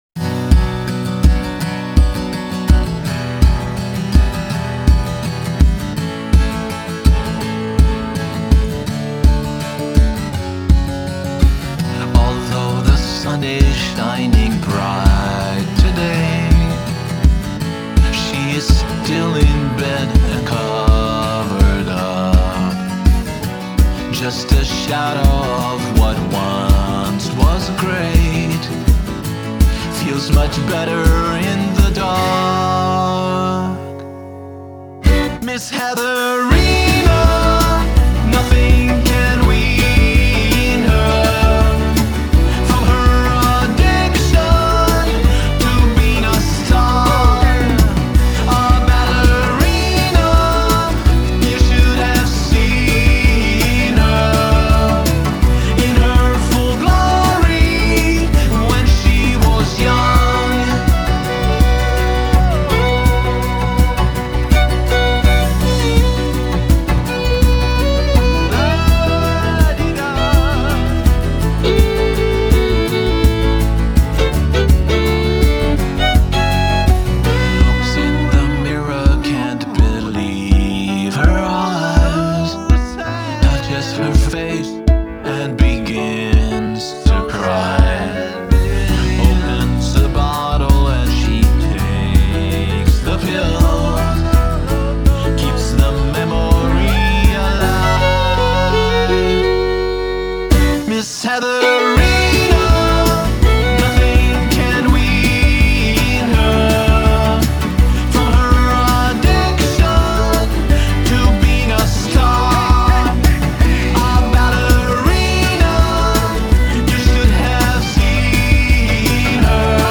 Genre: Pop Rock, Adult Alternative Pop/Rock